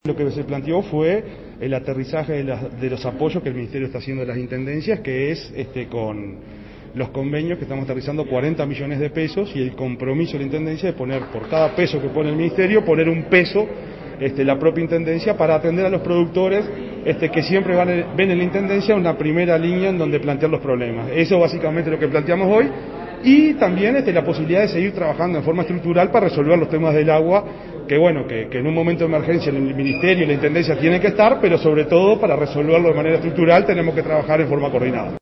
En la tarde de este jueves en el Congreso de Intendentes el subsecretario del MGAP, Ignacio Buffa,  indicó que se trabaja junto a los gobiernos departamentales de manera coordinada en el marco de la emergencia agropecuaria “para  hacer el aterrizaje de los recursos lo más rápido posible».
En rueda de prensa Buffa explicó que se estará “aterrizando” a las Intendencias unos 40 millones de pesos con el compromiso  de  las mismas de que  por cada peso que pone el Ministerio,  podrán  un peso las Intendencias, alcanzando un total de  80 millones de pesos para atender a los productores que están en zona de emergencia.